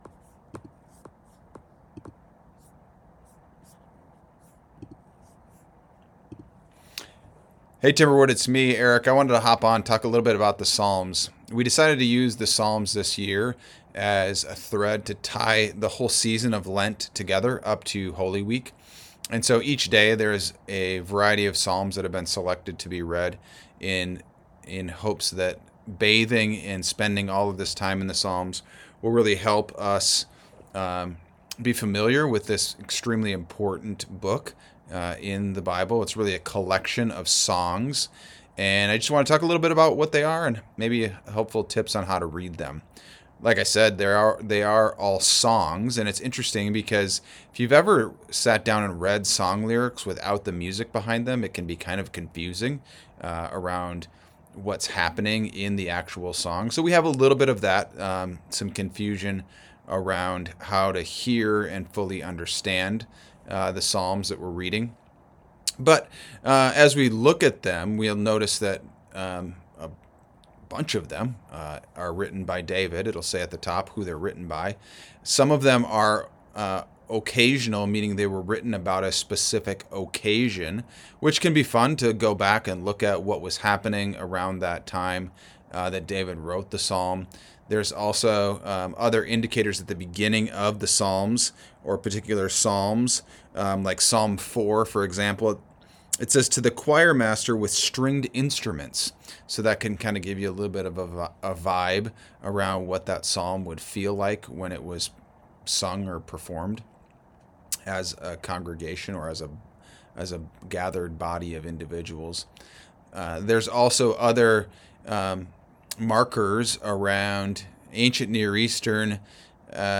Lent Psalm Readings